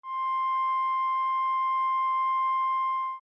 C6.mp3